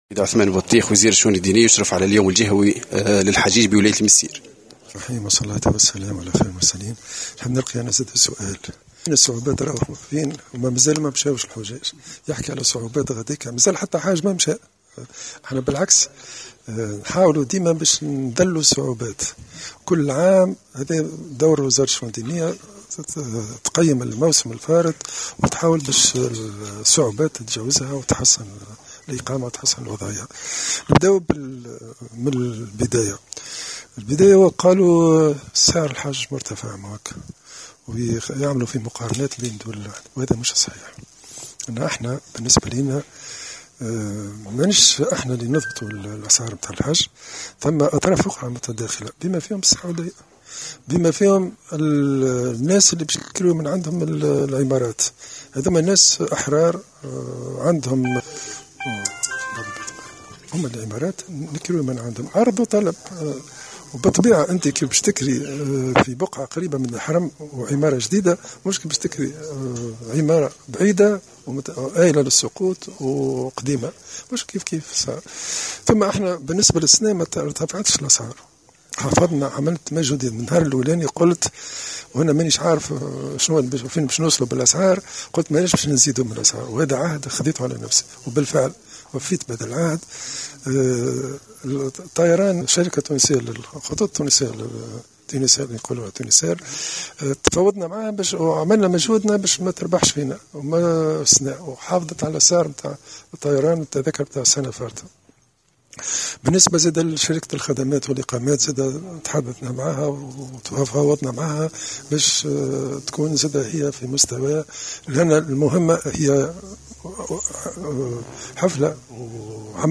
أكد وزير الشؤون الدينية، عثمان بطيخ، في تصريح للجوهرة أف أم لدى اشرافه علي اليوم الجهوي للحجيج في المنستير اليوم الخميس، أن السلطات التونسية ليست هي المسؤولة عن ارتفاع أسعار الحج وإنما أصحاب الإقامات في المملكة، حيث حافظت شركة الخطوط التونسية على نفس اسعار الموسم الماضي، مشيرا إلى أن تونس سعت لإيواء حجيجها الميامين في إقامات قريبة من الحرم وفي بنايات جيدة.